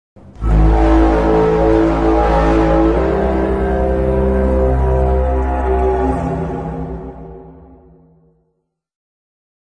Tripod Horn
war-of-the-worlds-tripod-sound.mp3